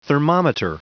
Prononciation du mot thermometer en anglais (fichier audio)
Prononciation du mot : thermometer